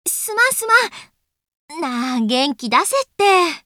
System Voice